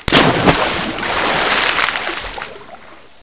Splash1
SPLASH1.WAV